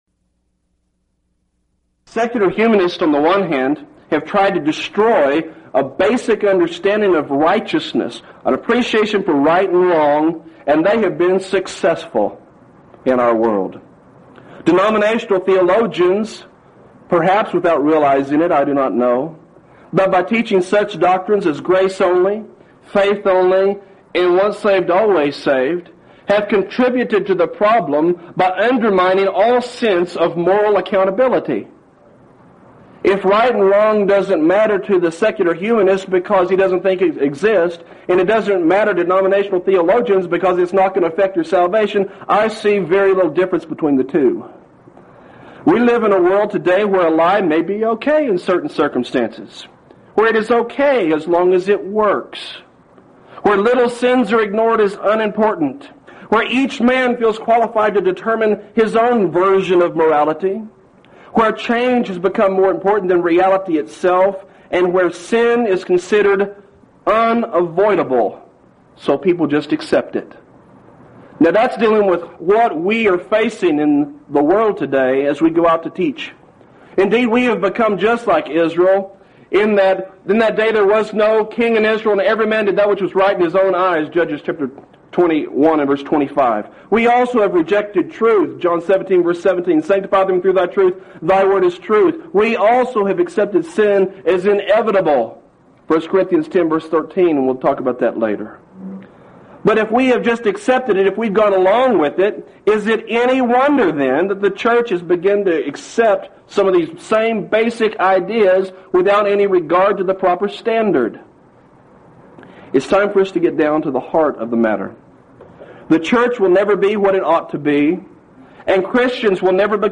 Event: 2nd Annual Lubbock Lectures
lecture